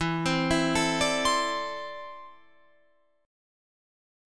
Listen to E7sus4#5 strummed